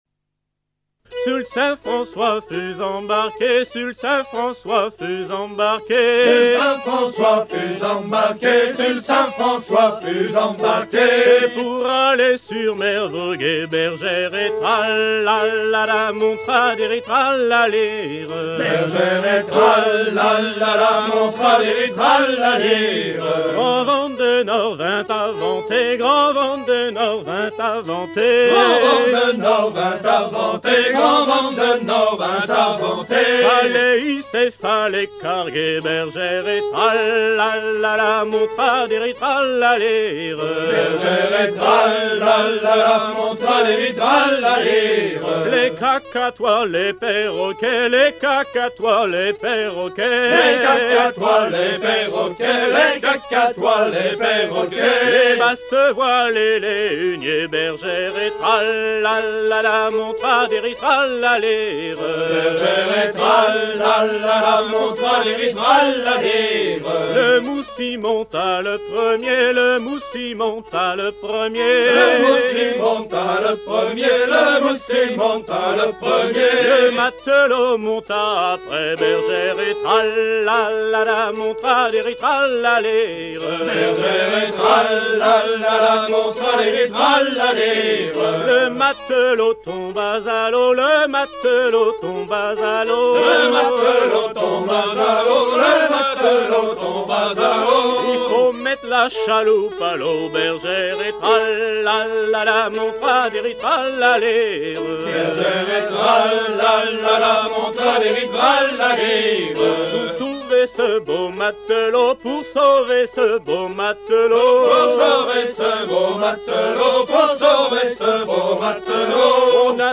Région ou province Îles vendéennes
Fonction d'après l'analyste danse : danse carrée ;
Genre laisse